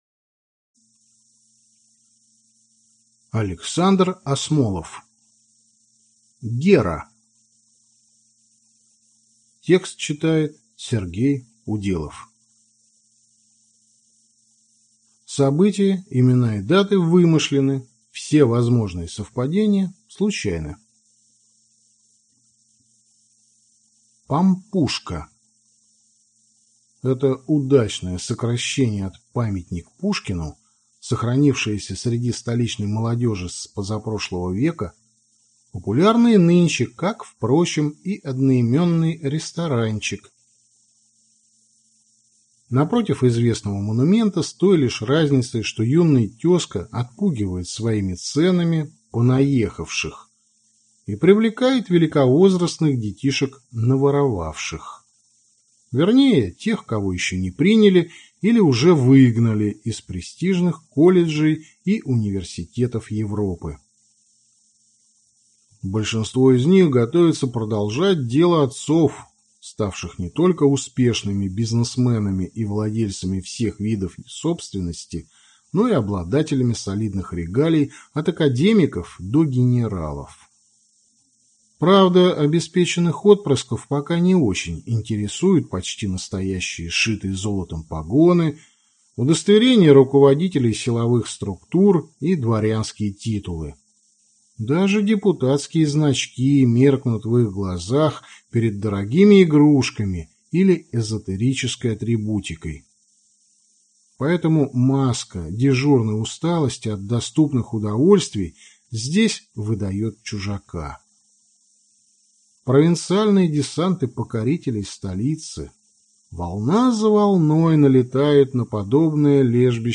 Аудиокнига Гера. Детектив | Библиотека аудиокниг